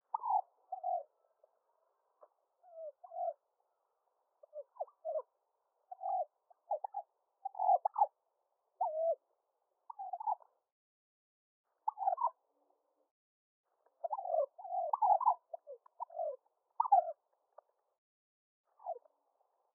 Animal_Turkey_Chirps.ogg